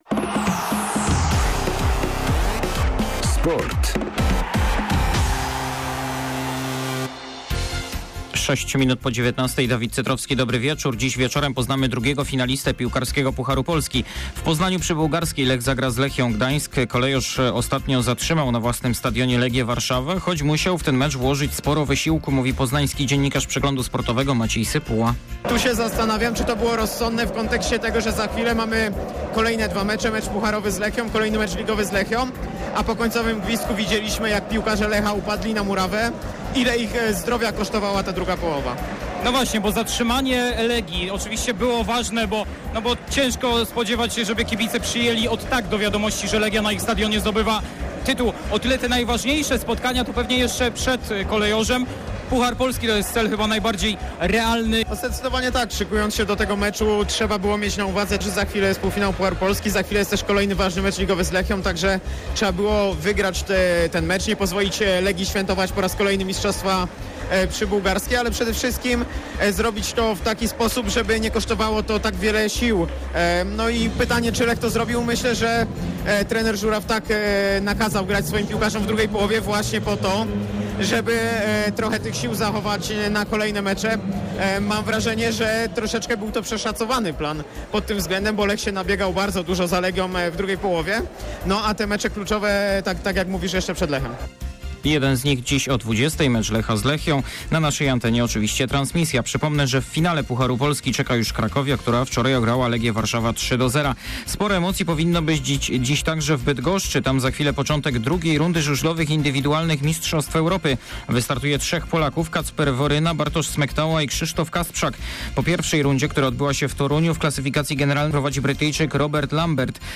08.07. SERWIS SPORTOWY GODZ. 19:05